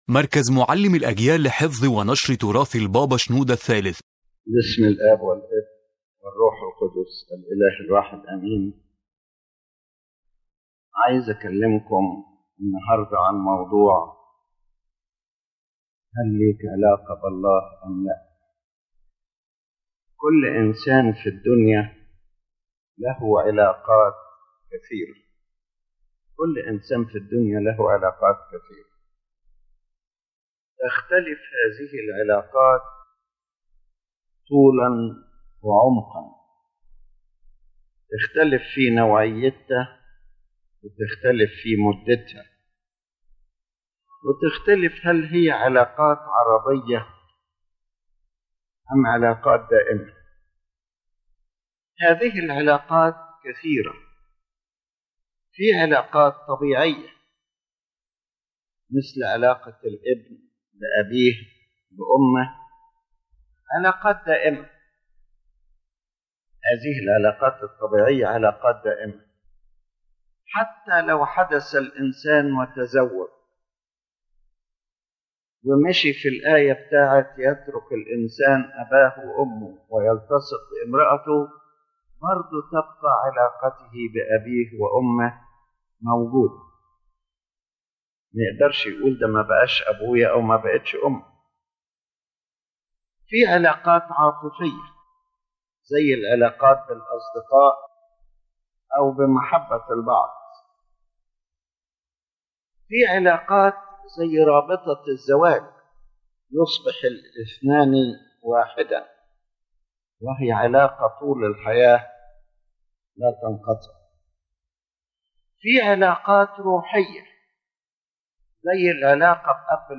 ⬇ تحميل المحاضرة Pope Shenouda III speaks about the essence of the true relationship between a human being and God.